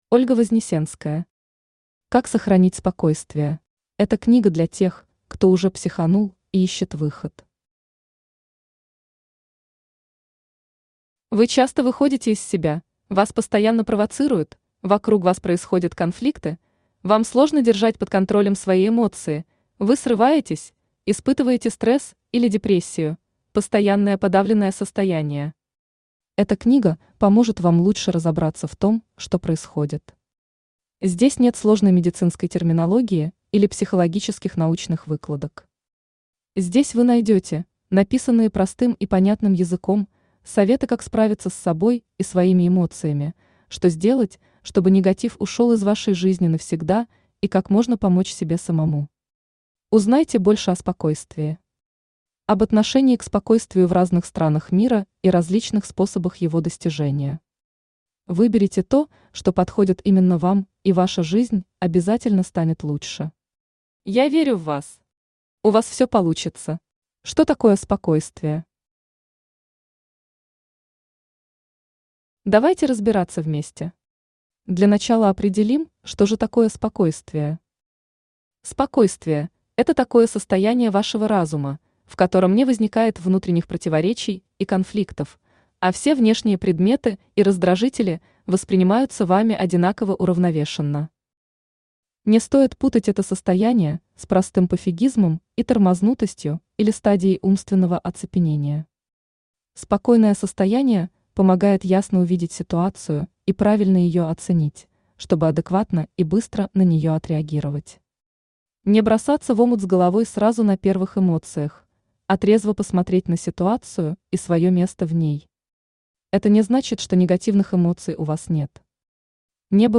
Аудиокнига Как сохранить спокойствие | Библиотека аудиокниг
Aудиокнига Как сохранить спокойствие Автор Ольга Сергеевна Вознесенская Читает аудиокнигу Авточтец ЛитРес.